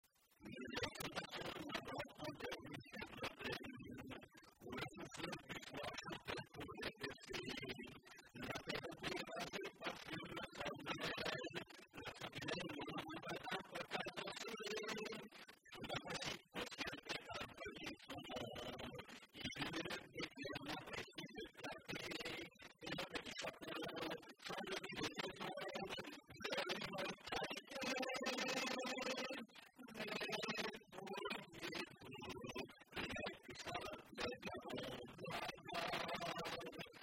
Localisation Bessay
Genre strophique
Catégorie Pièce musicale inédite